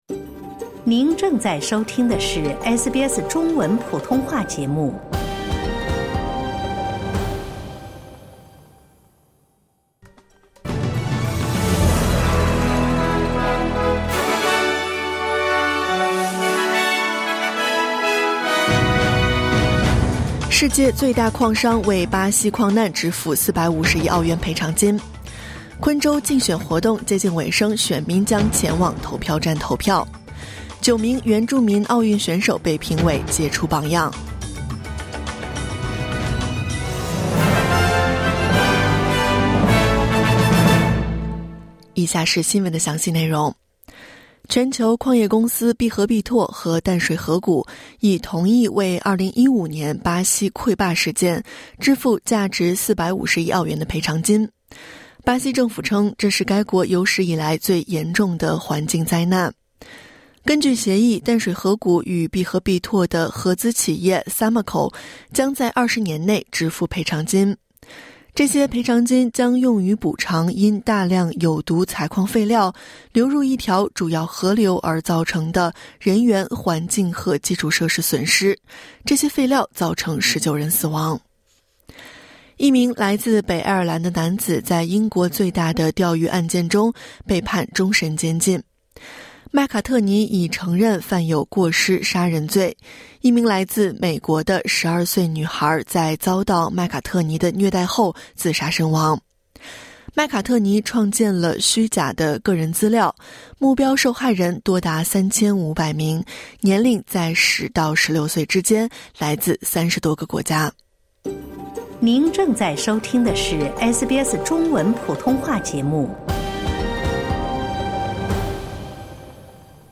SBS早新闻（2024年10月26日）
SBS Mandarin morning news Source: Getty / Getty Images